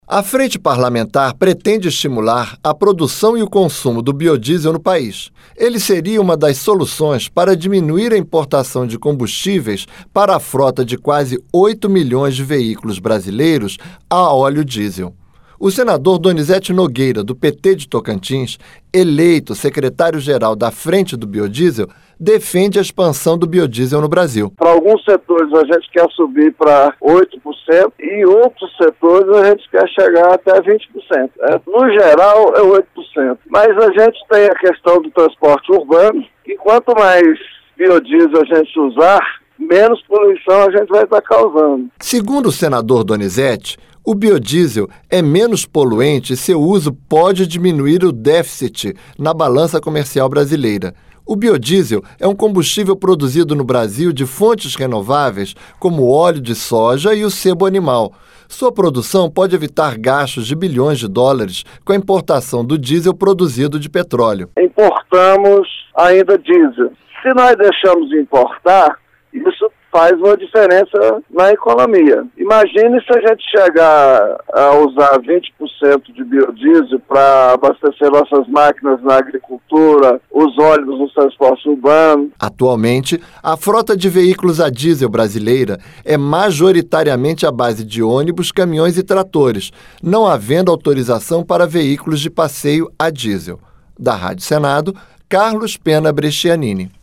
O senador Donizete Nogueira, do PT de Tocantins, eleito secretário-geral da frente do biodiesel, defende a expansão do biodiesel no Brasil: (Donizete Nogueira) Pra alguns setores, a gente quer subir para 8% e outros setores, a gente quer chegar a até 20%.